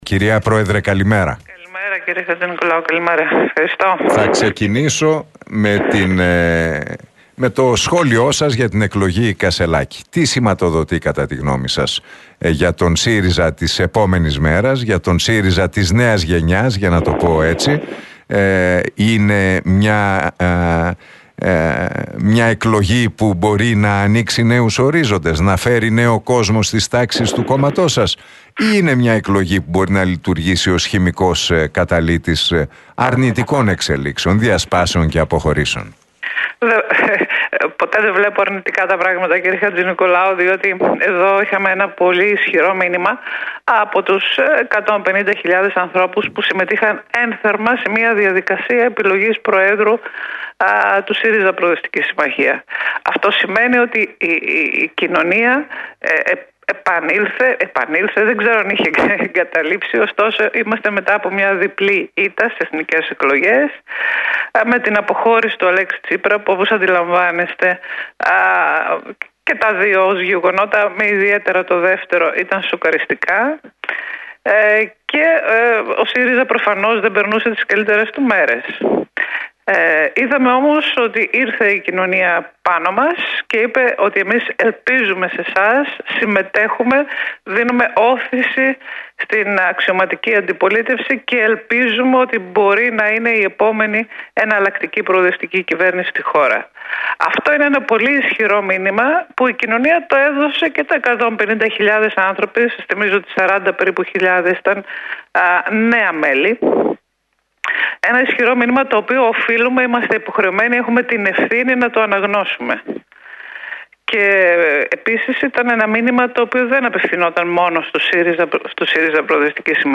«Είχαμε ένα ισχυρό μήνυμα από τους 150.000 ανθρώπους που συμμετείχαν ένθερμα στη διαδικασία επιλογής προέδρου του ΣΥΡΙΖΑ. Η κοινωνία επανήλθε, δεν ξέρω αν είχε εγκαταλείψει, ωστόσο είμαστε μετά από μια διπλή ήττα στις εθνικές εκλογές με την αποχώρηση του Αλέξη Τσίπρα, που και τα δύο ήταν σοκαριστικά» δήλωσε η βουλευτής του ΣΥΡΙΖΑ και αντιπρόεδρος της Βουλής, Όλγα Γεροβασίλη, μιλώντας στην εκπομπή του Νίκου Χατζηνικολάου στον Realfm 97,8.